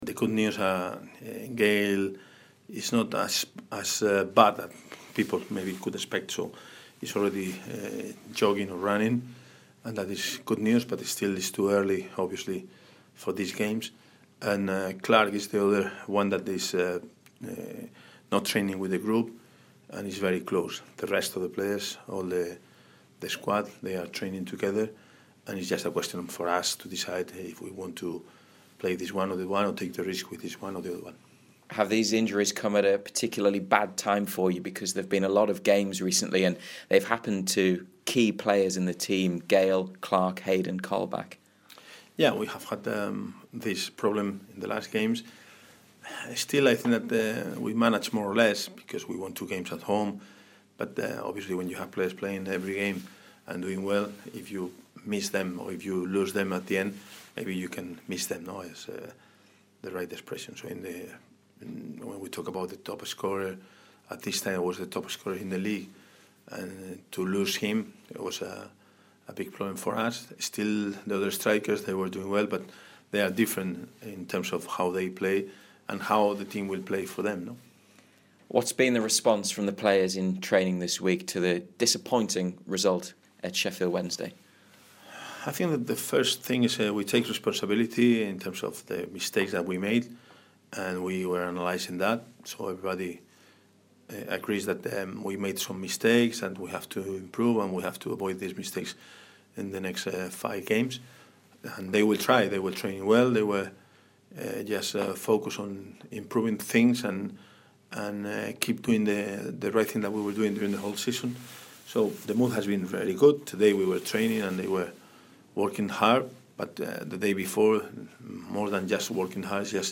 Rafa Benitez speaks to BBC Newcastle pre-Leeds